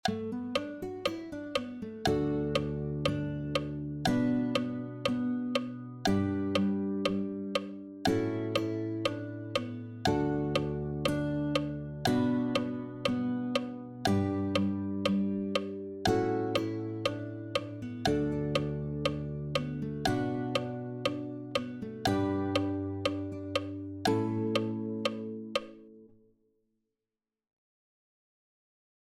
for solo guitar